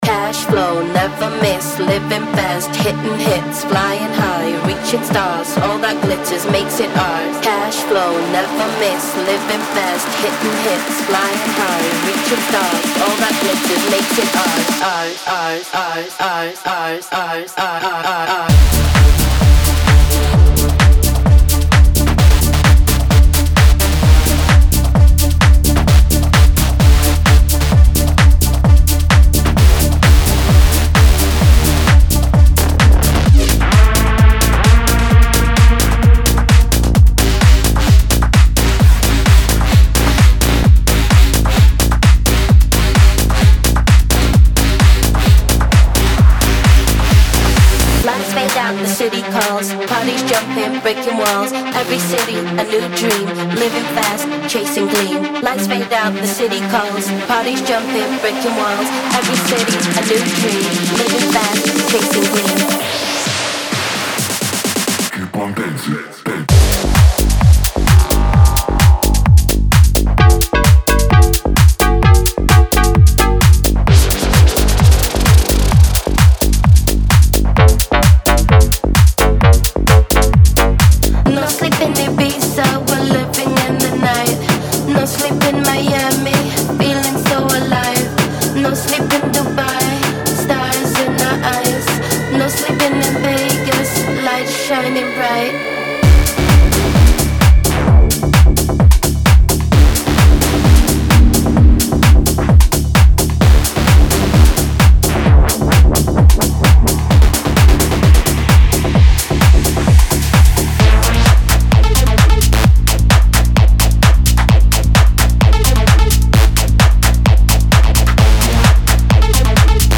デモサウンドはコチラ↓
Genre:Tech House
130 BPM